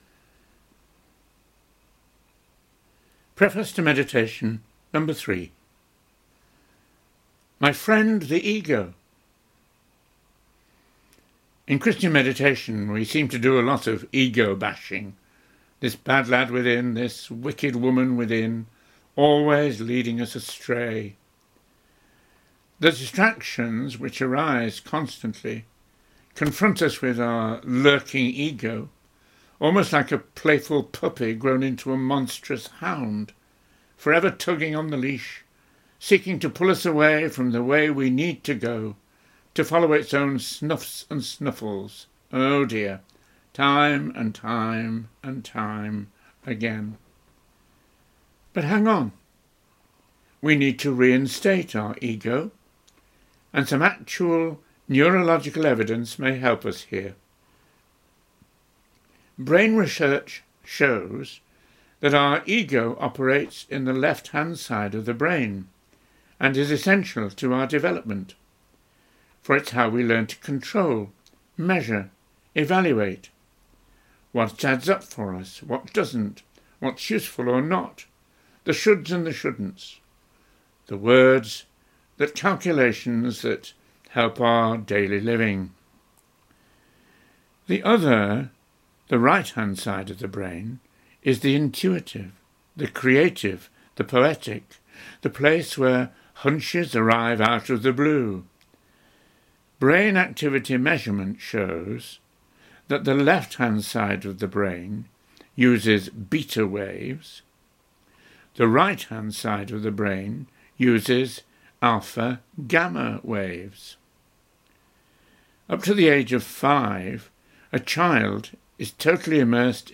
Recorded Talks